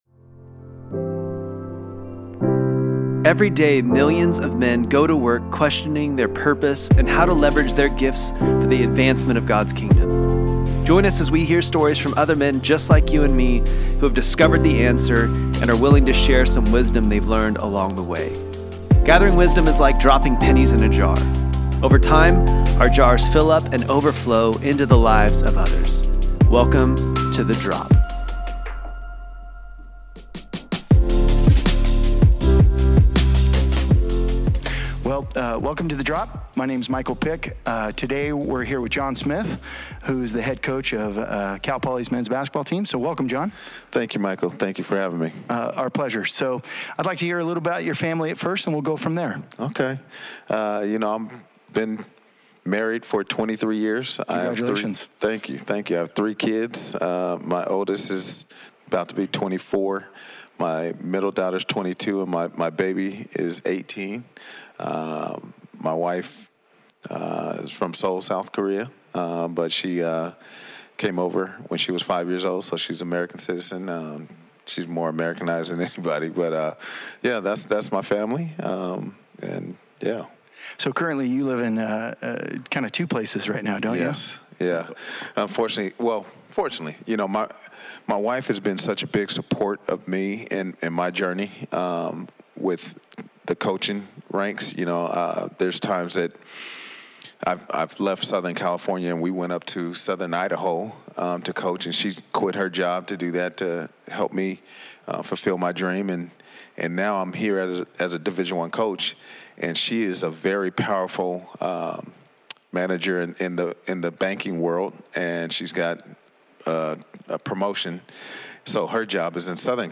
The drop is a podcast featuring conversations with men just like you and me who have discovered how to leverage their gifts for the advancement of God’s kingdom.